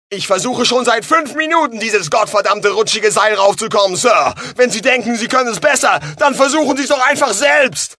US-Ranger: